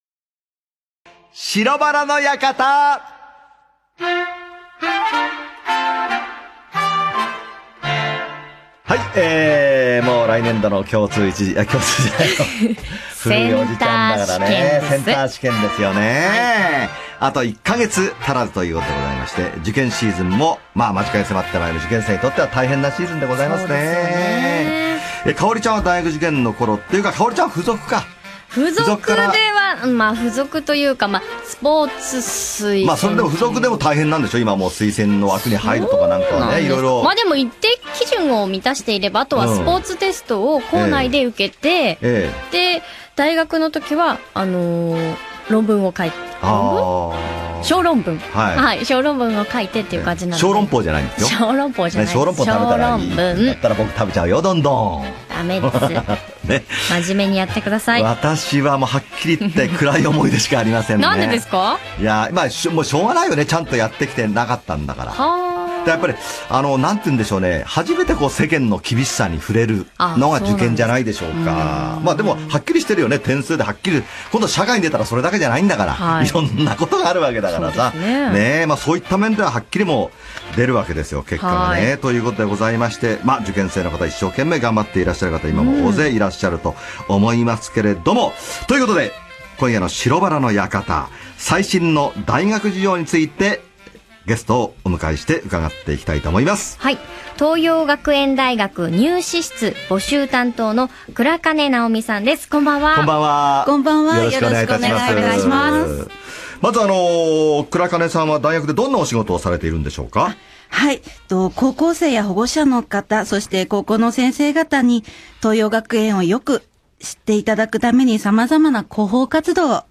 文化放送（AM1134KHz）の情報番組